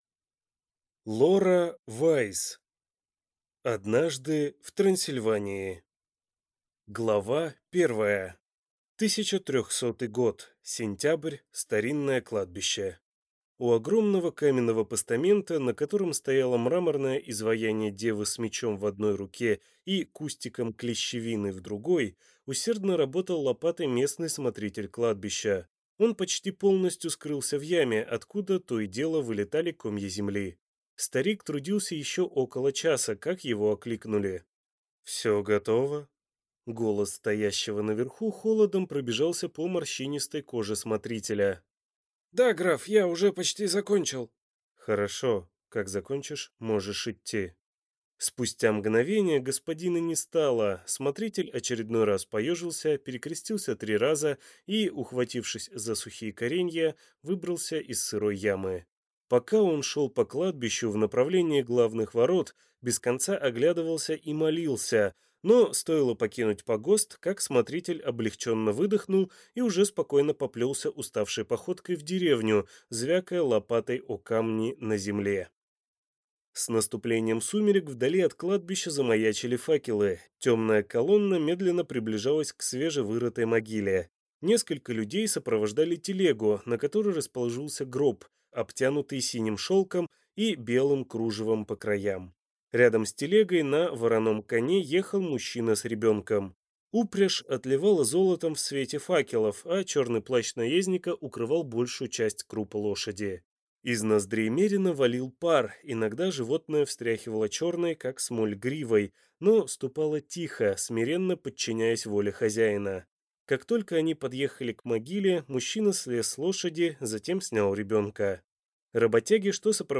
Аудиокнига Однажды в Трансильвании | Библиотека аудиокниг
Прослушать и бесплатно скачать фрагмент аудиокниги